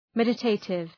Προφορά
{‘medə,teıtıv}